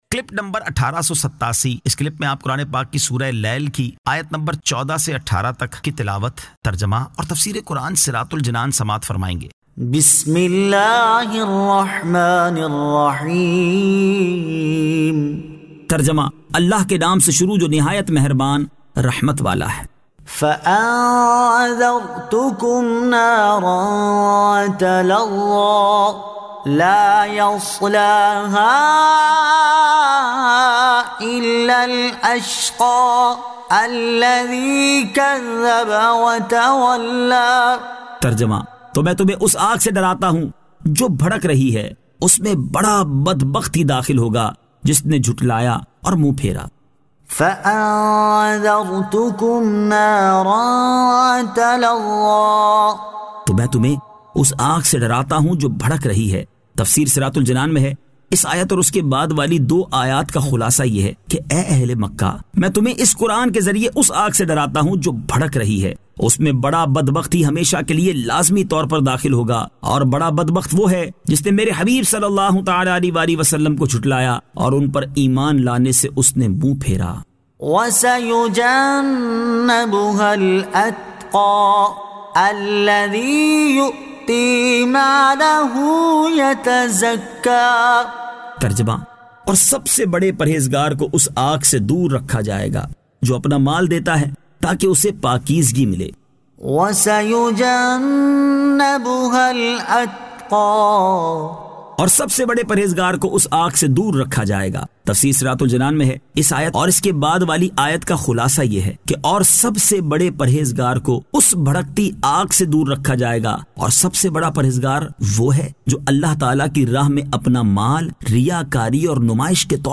Surah Al-Lail 14 To 18 Tilawat , Tarjama , Tafseer
2025 MP3 MP4 MP4 Share سُوَّرۃُ الْلَیْلْ آیت 14 تا 18 تلاوت ، ترجمہ ، تفسیر ۔